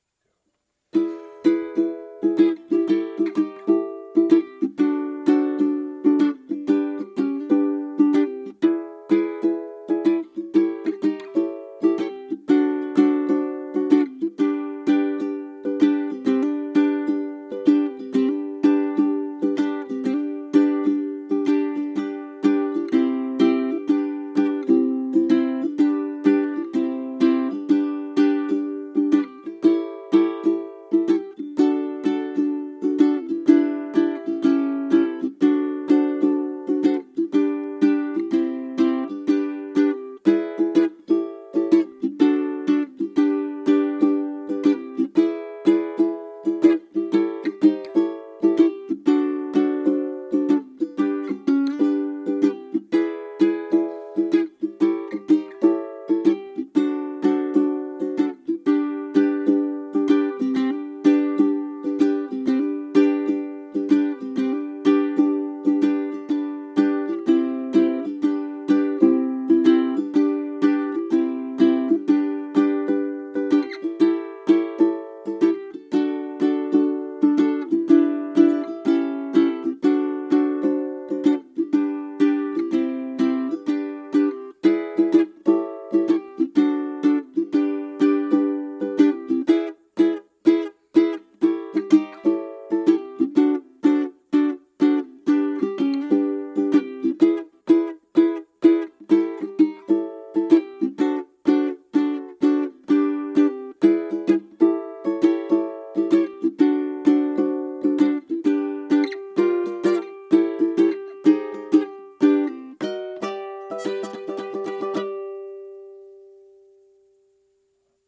I love my custom narrow body tenor, which is a thing of beauty, sounds great and is exactly the size I wanted it.